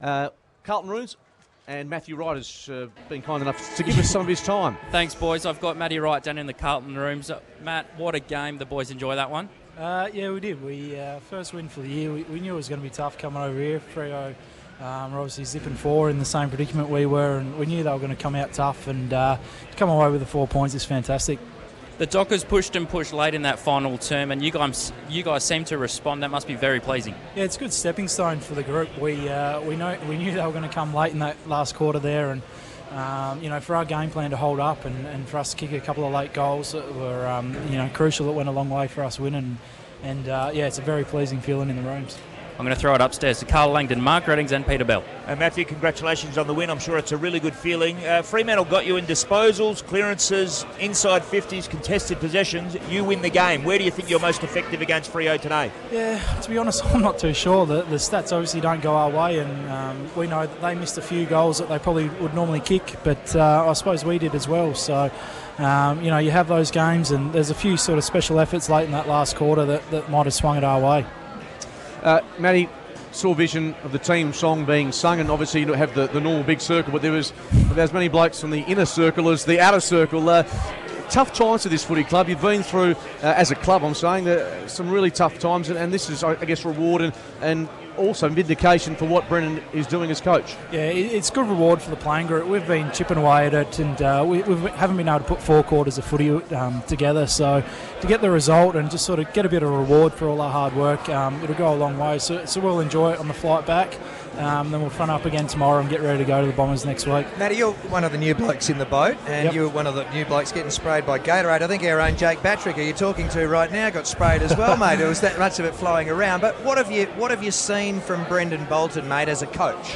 Matthew Wright Post Game Interview